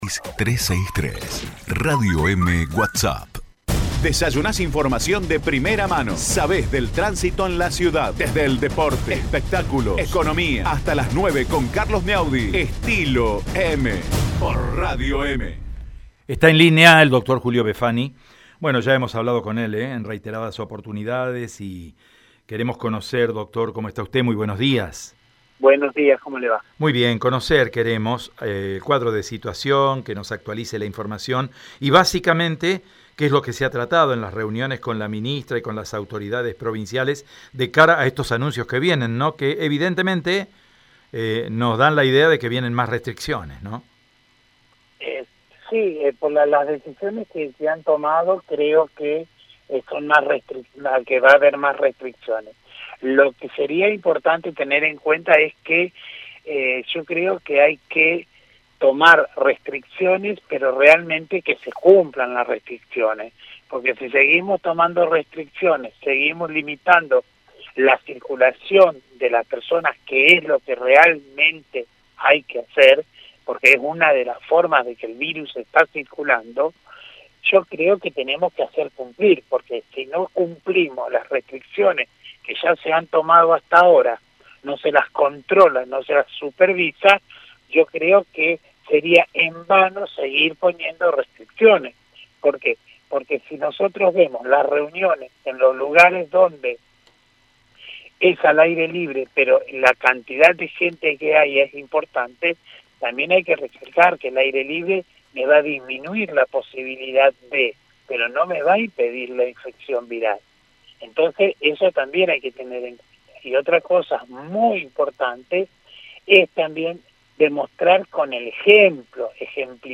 En diálogo con Radio EME